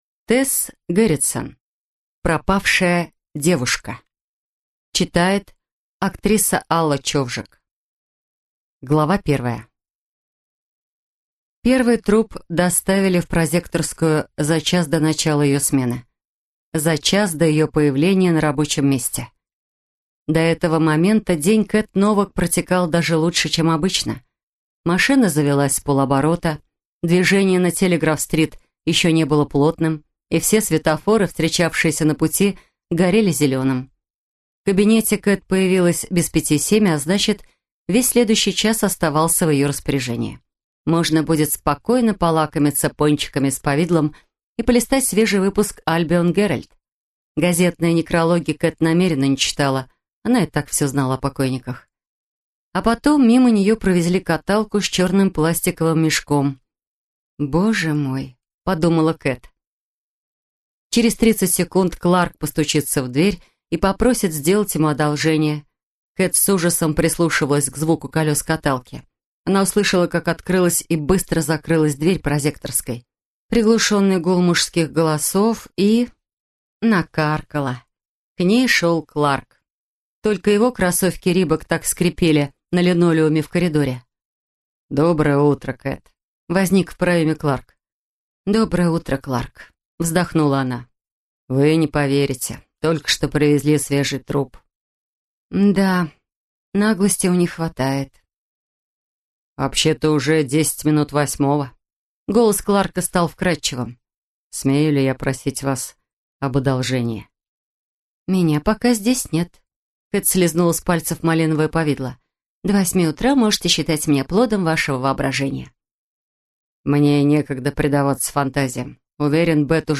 Пропавшая девушка | Тесс Герритсен (аудиокнига) Пропавшая девушка | Тесс Герритсен (аудиокнига) Продолжительность: 9:58